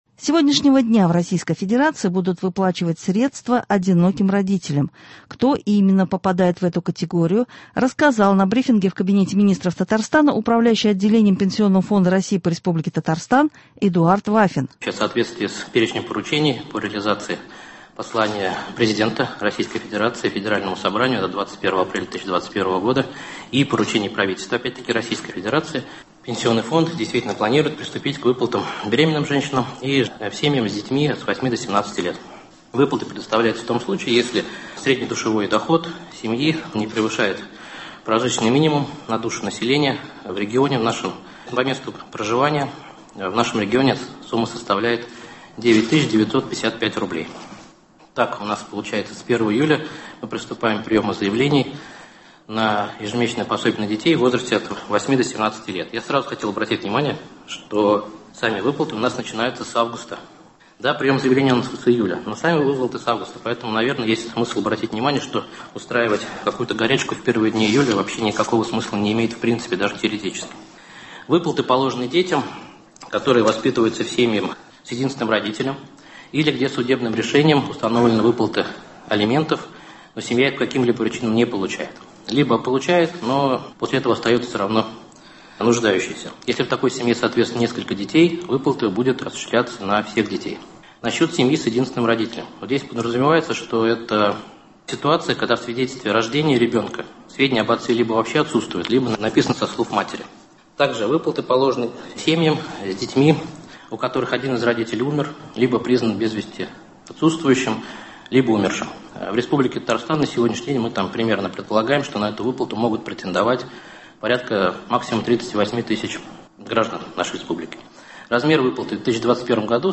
С сегодняшнего дня в Российской Федерации будут выплачивать средства одиноким родителям. Кто именно попадает в эту категорию – рассказал на брифинге в Кабинете министров Татарстана Управляющий Отделением Пенсионного фонда России по Республике Татарстан Эдуард ВАФИН